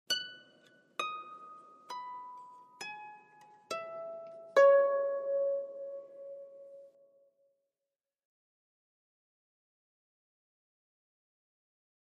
Harp, Slow Arpeggio, Type 1